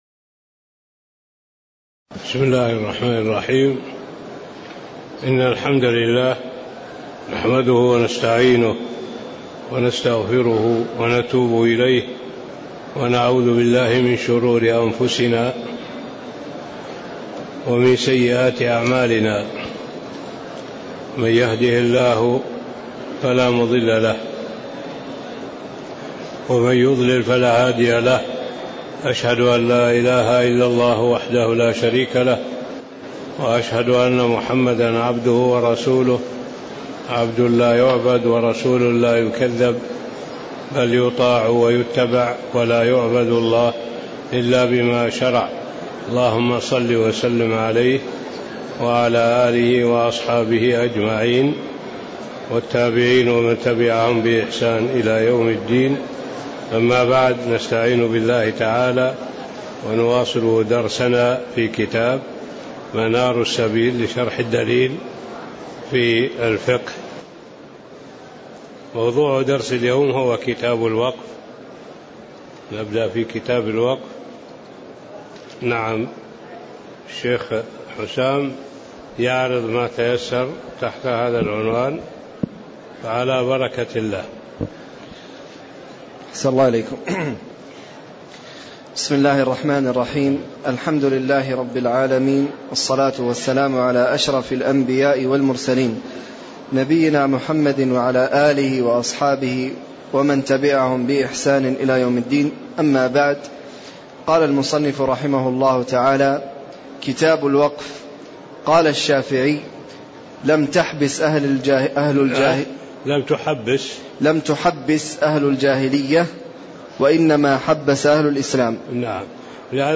تاريخ النشر ٢٣ ربيع الأول ١٤٣٧ هـ المكان: المسجد النبوي الشيخ